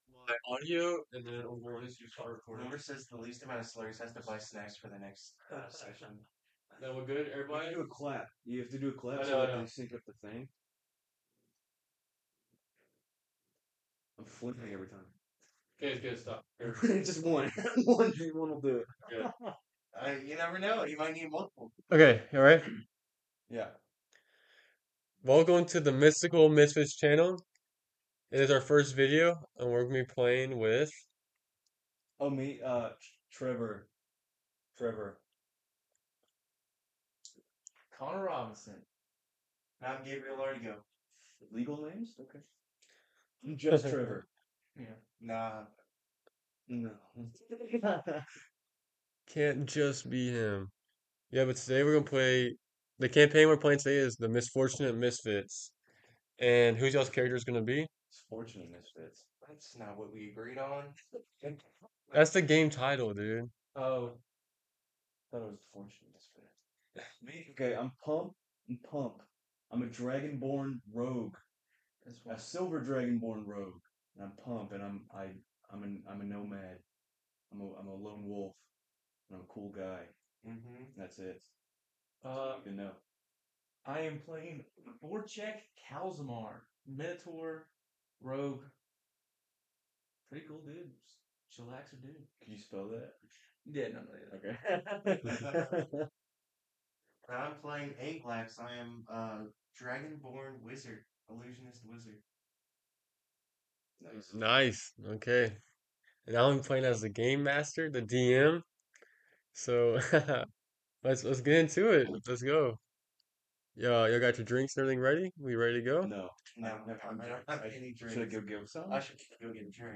Format: Audio RPG
Writing: Improvised Voices: Full cast
Soundscape: Voices only